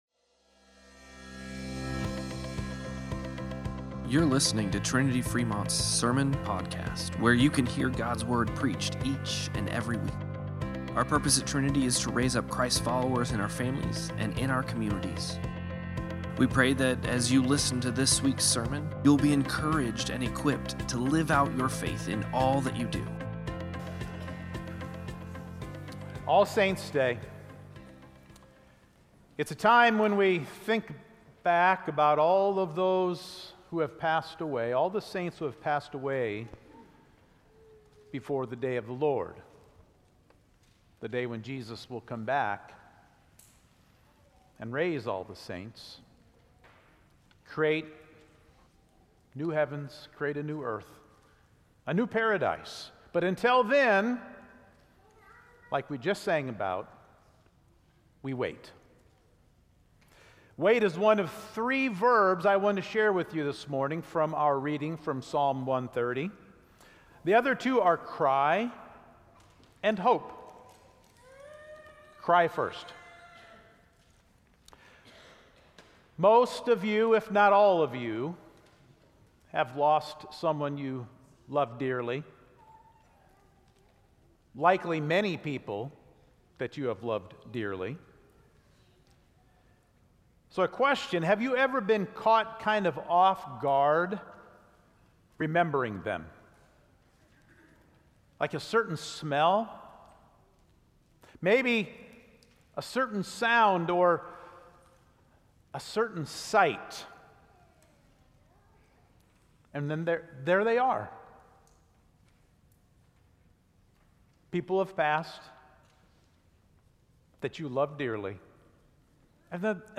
Sermon-Podcast-11-3-25.mp3